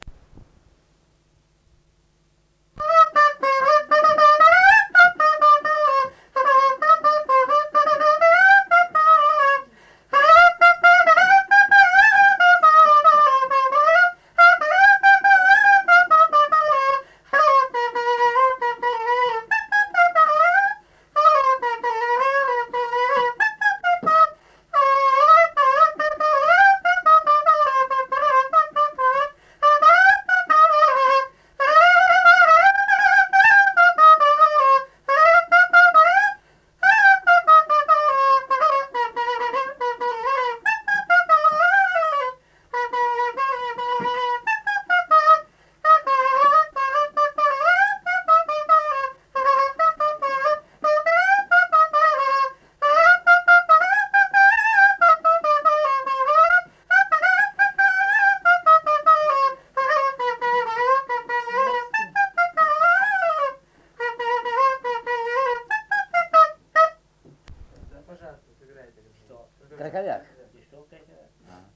Dalykas, tema šokis
Erdvinė aprėptis Adutiškis
Atlikimo pubūdis instrumentinis
Instrumentas klarnetas